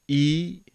Ī - long vowel sound | 483_14,400